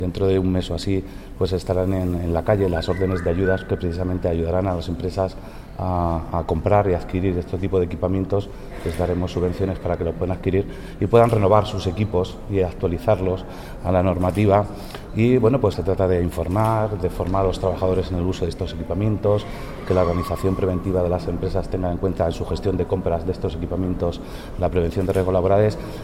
El delegado de la Junta en Guadalajara, Alberto Rojo, habla de la constitución de la mesa de trabajo para la prevención de riesgos laborales en el sector de la logística y el transporte